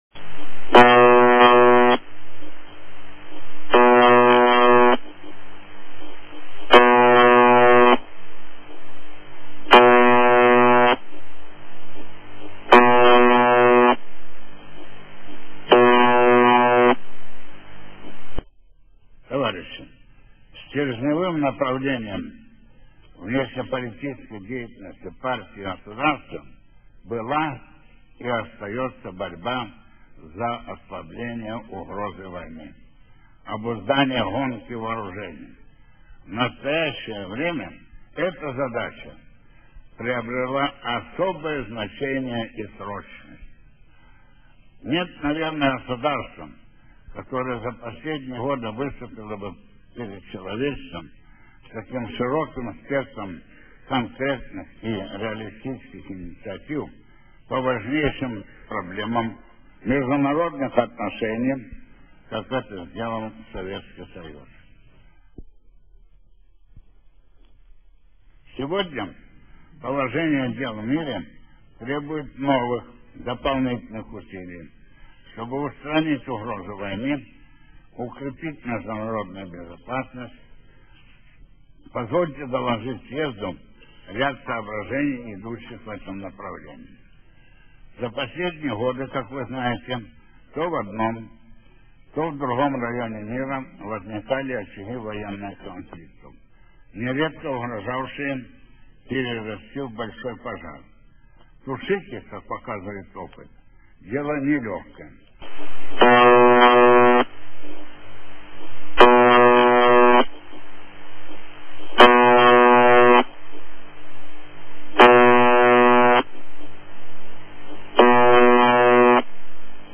Жужжалка 4625 экстренное сообщение